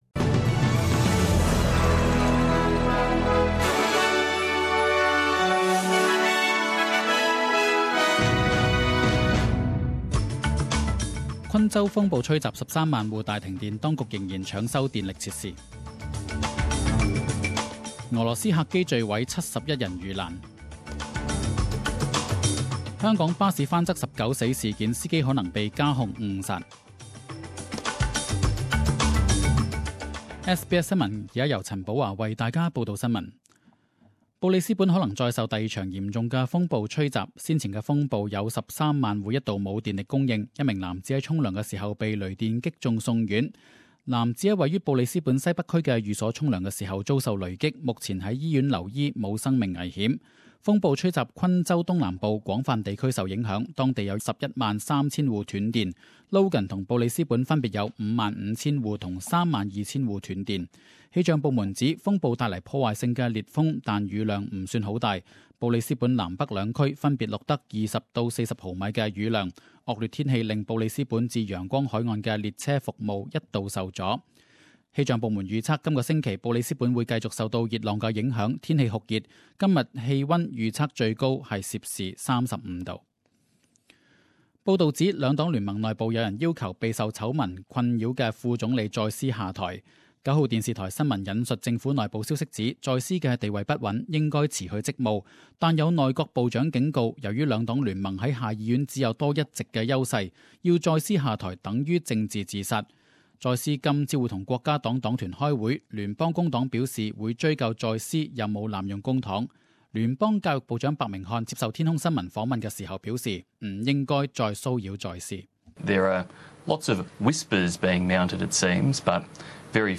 十點鐘新聞報導 (2月12日)